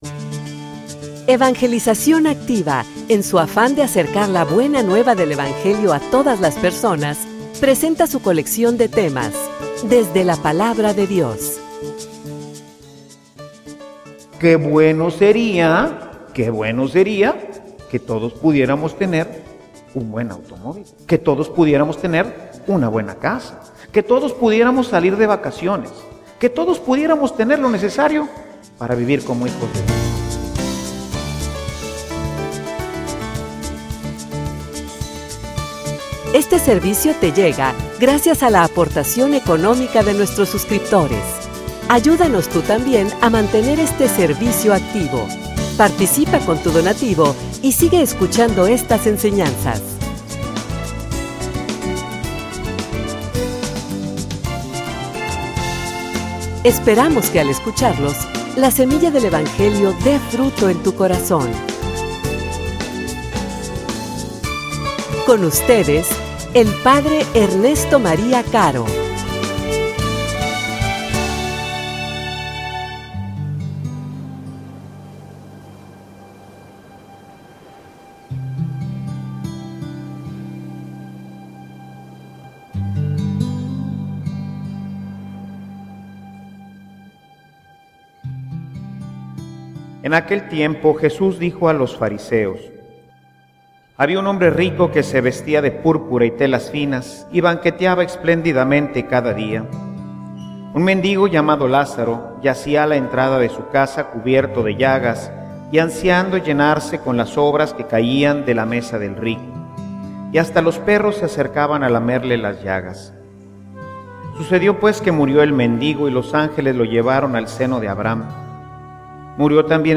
homilia_Donde_quieres_que_te_paguen.mp3